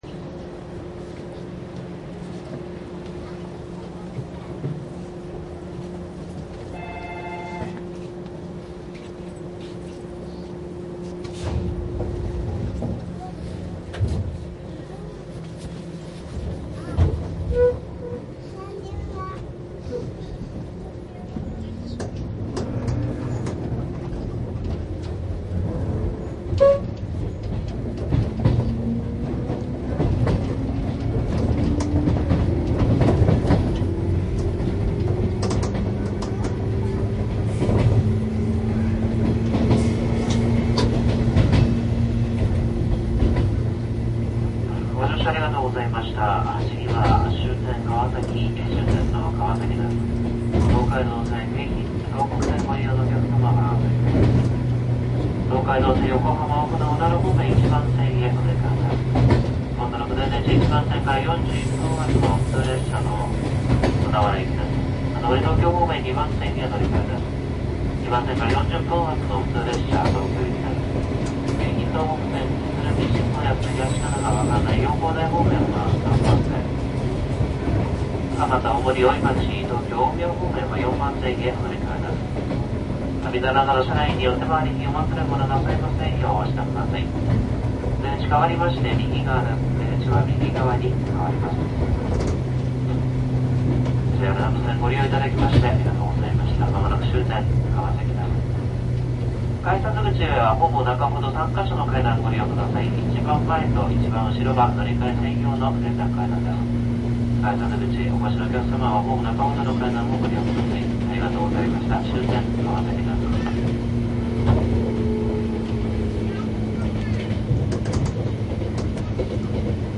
南武線 川崎方面 103系走行音♪
収録車両： モハ１０３-２０１
収録機材： ソニーTCD－D7 (DAT)
収録マイク ： ソニーＥＣＭ959
府中本町で停車する際に急制動をかけています（パシャーとエアーが抜ける音が聞こえます）。
■【普通】立川→川崎 モハ103-201（初期型主電動機）
マスター音源はデジタル44.1kHz16ビット（マイクＥＣＭ959）で、これを編集ソフトでＣＤに焼いたものです。